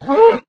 mob / cow / hurt2.ogg
hurt2.ogg